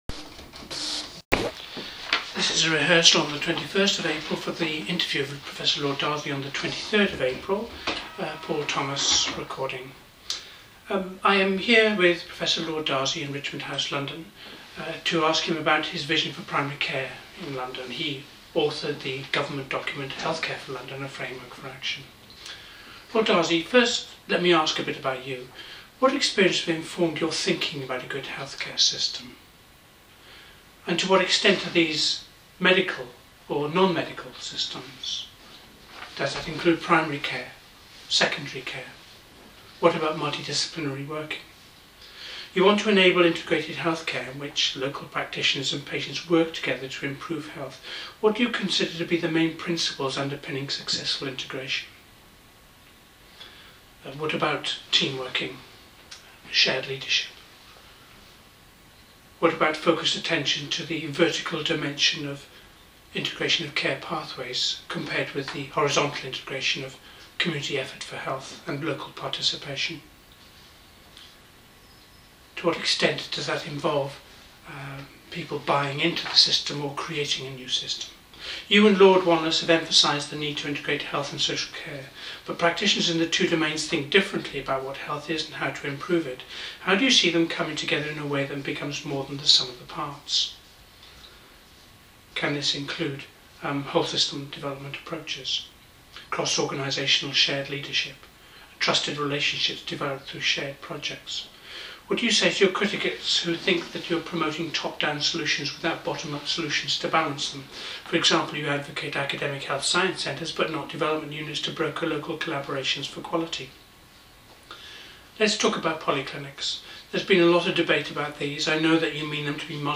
Test interview
This is a test interview.